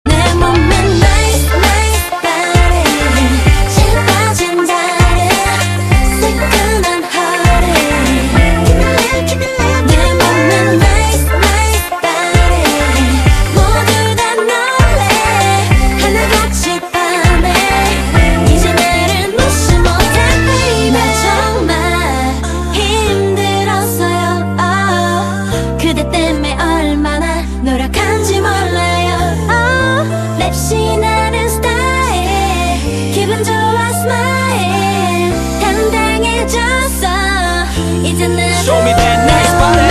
M4R铃声, MP3铃声, 日韩歌曲 16 首发日期：2018-05-15 20:54 星期二